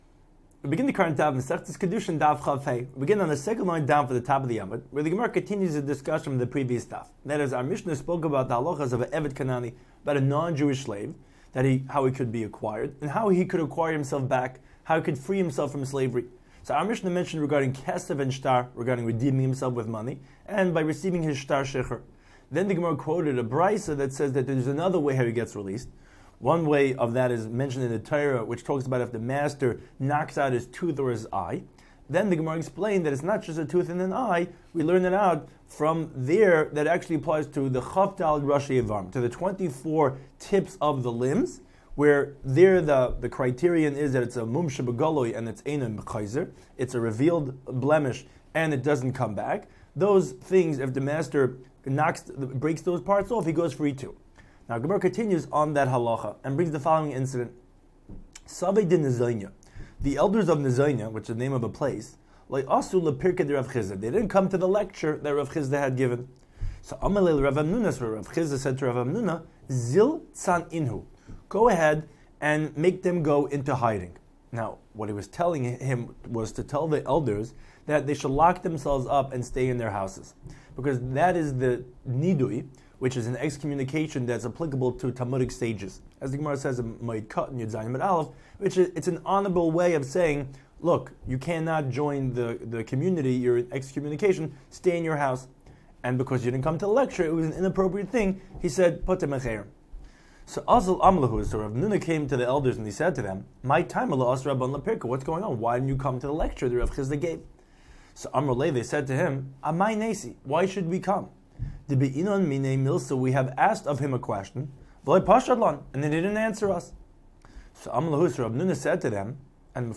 Daf Hachaim Shiur for Kidushin 25